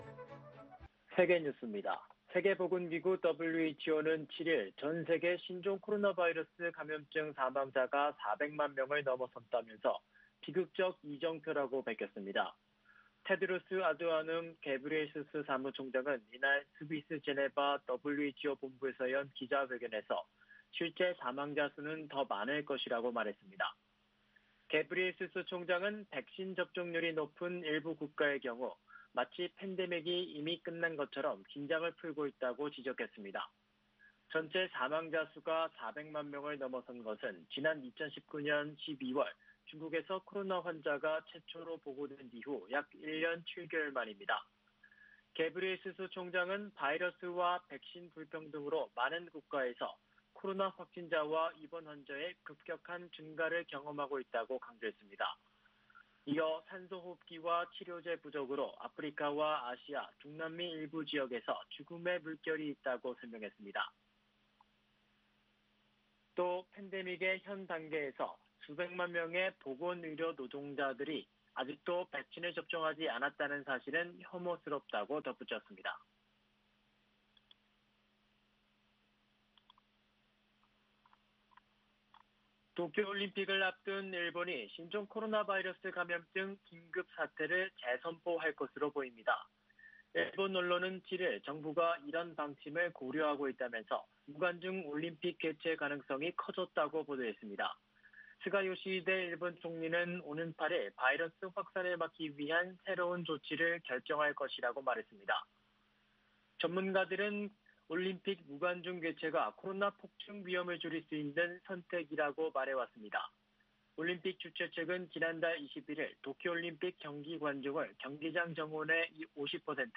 VOA 한국어 아침 뉴스 프로그램 '워싱턴 뉴스 광장' 2021년 7월 8일 방송입니다. 미 국무부 대북특별대표와 중국 외교부 한반도 사무 특별대표가 처음으로 전화통화를 했으나, 북한 문제에 대한 두 나라의 시각차가 여전하다고 전문가들이 말했습니다. 조 바이든 미국 정부가 제재정책에 대한 검토를 진행 중이라고 미국 언론이 보도했습니다. 조 바이든 미국 대통령과 시진핑 중국 국가주석이 머지않아 관여할 기회가 있을 것이라고 커트 캠벨 백악관 미 국가안보회의(NSC) 인도태평양 조정관이 밝혔습니다.